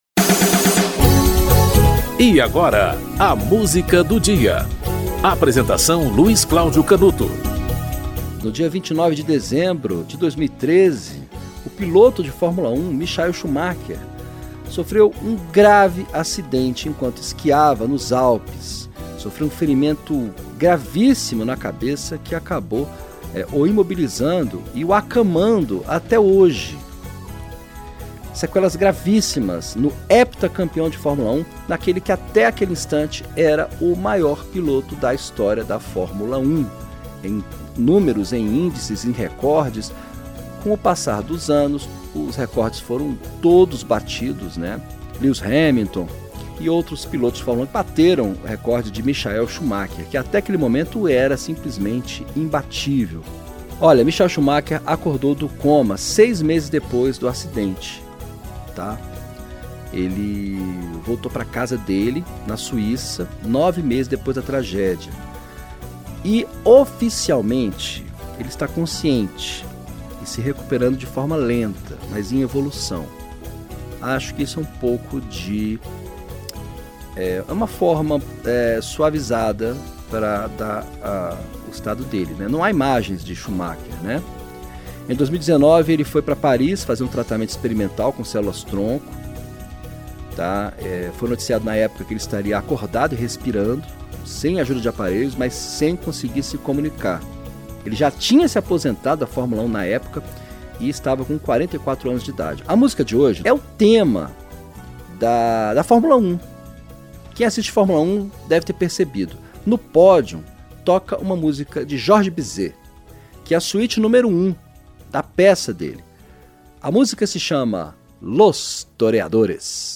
Orquestra do Festival de Londres - Los Toreadores (George Bizet)